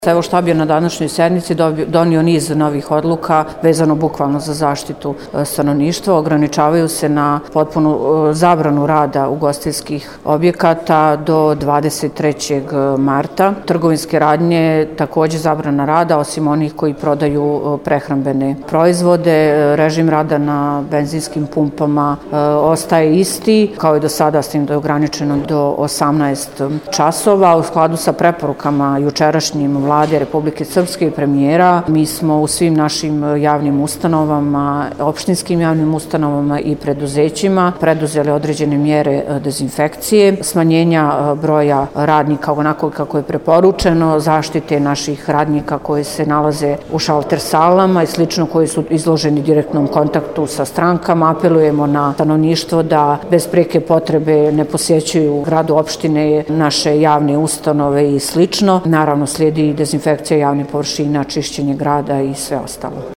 izjavu
DIVNA-ANICIC-NACELNIK-3.mp3